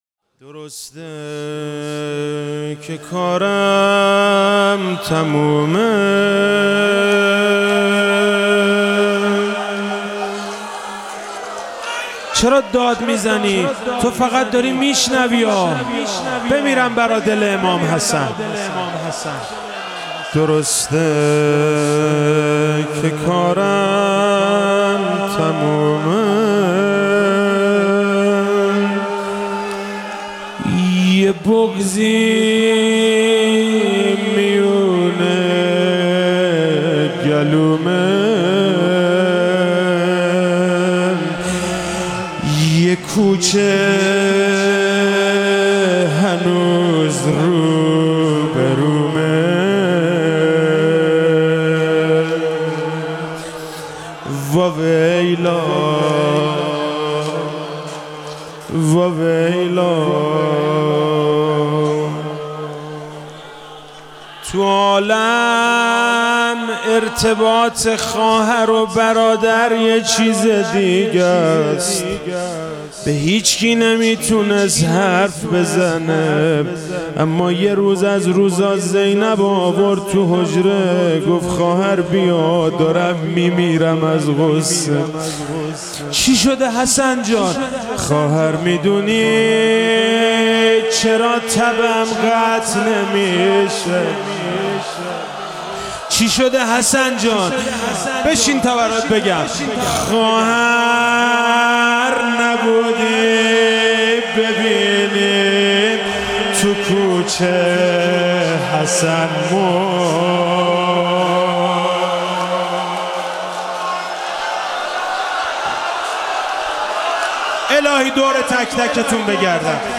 فاطمیه97 روایت 95 روز - شب دوم - روضه - درسته کارم تمومه یه بغضی